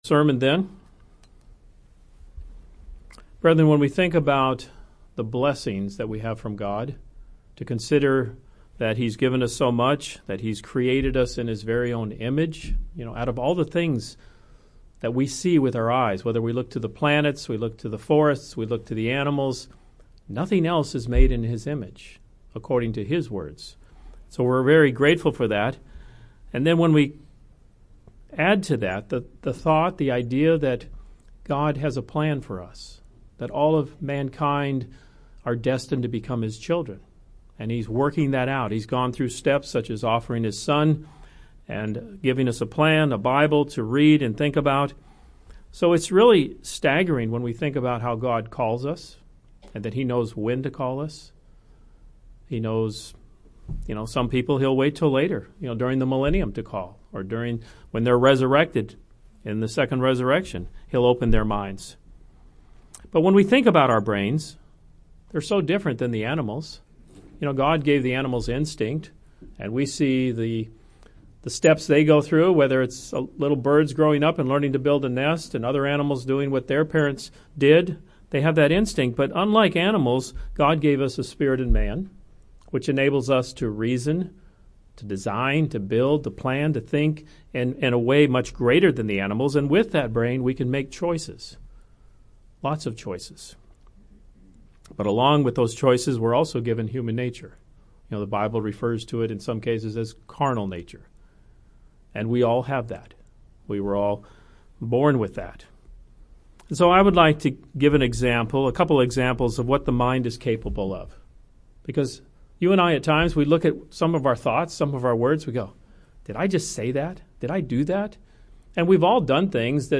Given in St. Petersburg, FL